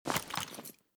mp5_holster.ogg.bak